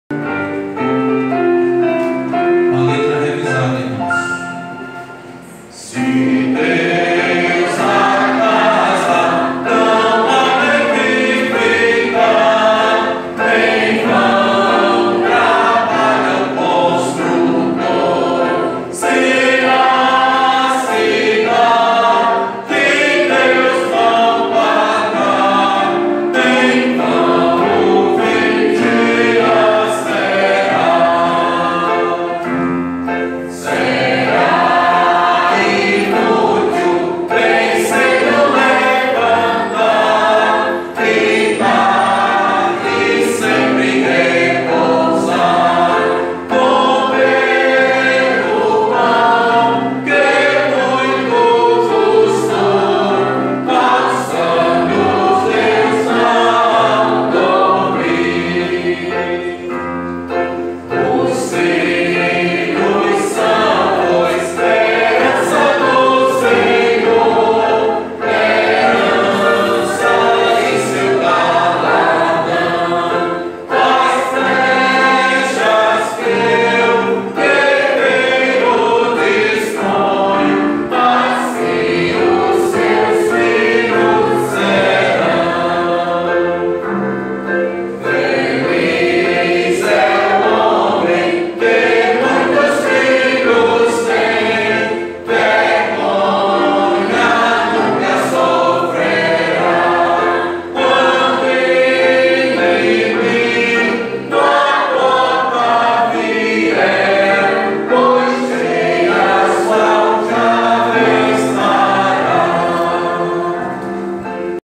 Métrica: 11. 8. 9. 8
salmo_127B_cantado.mp3